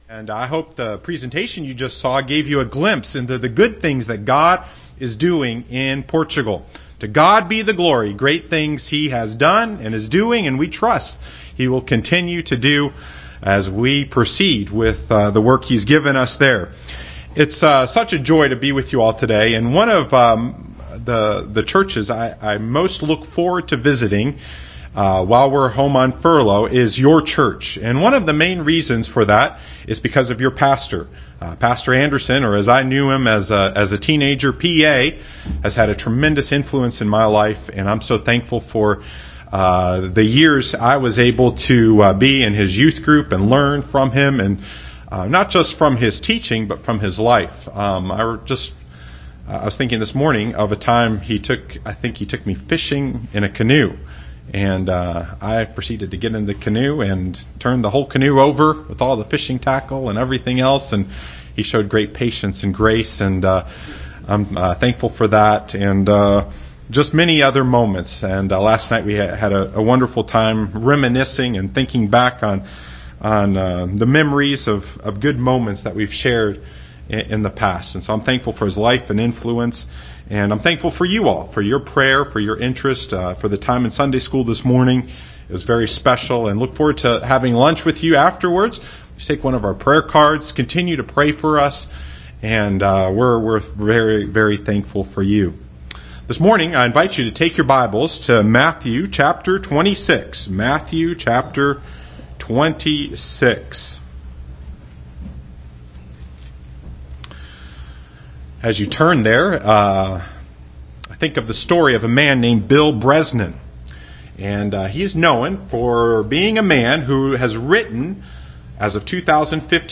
Matthew 26:6-13 Service Type: Sunday morning worship service Bible Text